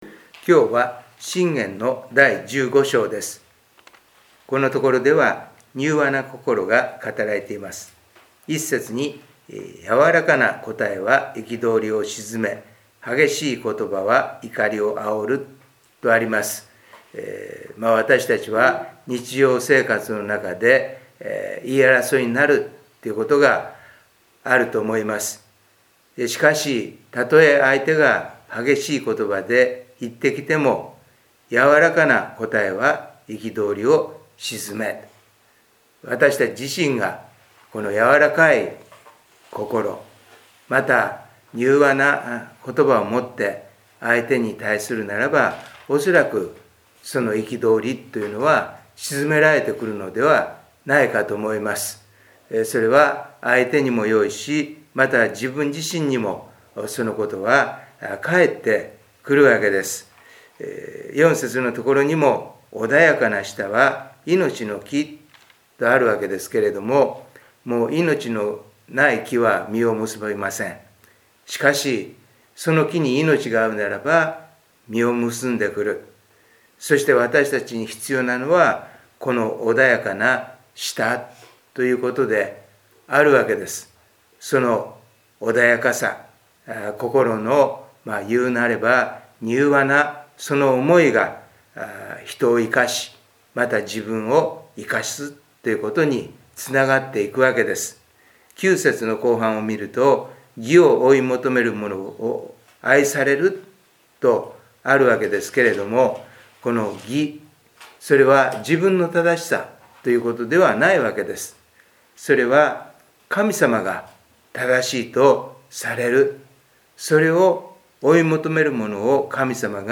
礼拝メッセージ2025│日本イエス・キリスト教団 柏 原 教 会